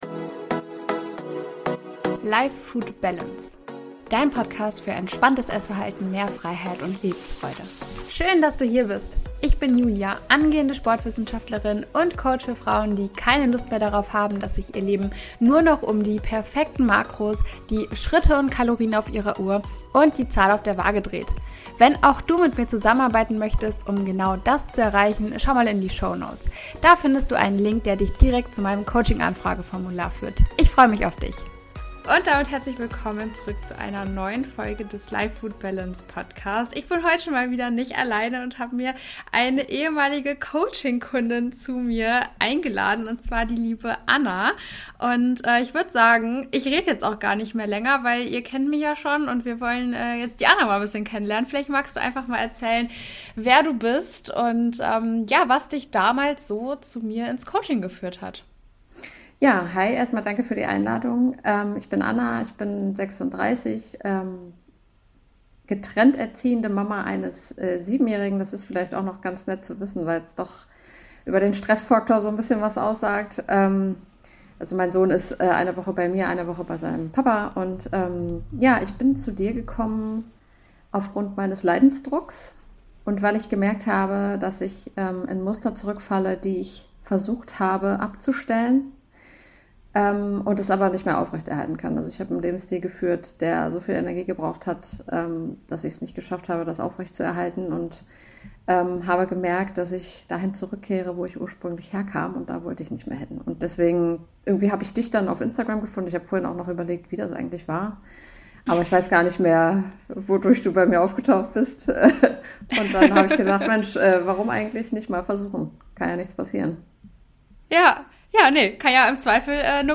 ÜBERGEWICHT, UMGANG MIT SÜßIGKEITEN & ALTE GEWOHNHEITEN - Interview